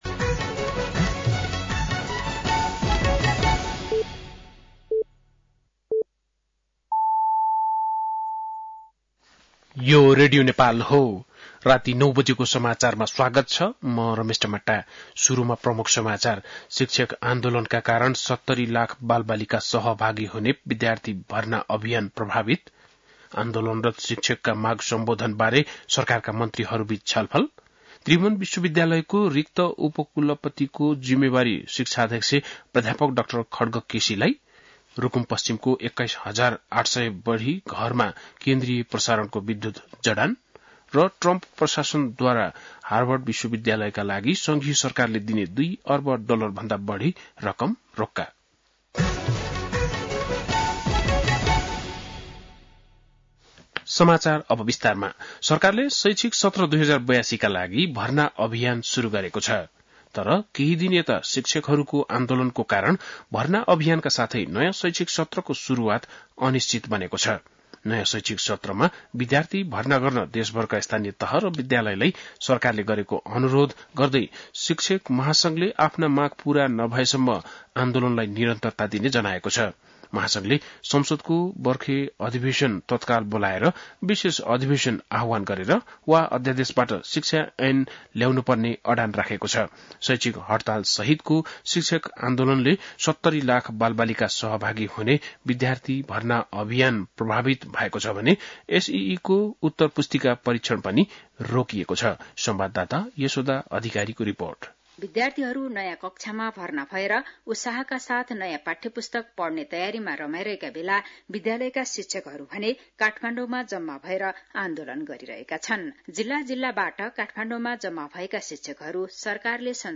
बेलुकी ९ बजेको नेपाली समाचार : २ वैशाख , २०८२
9-pm-nepali-news-1-2.mp3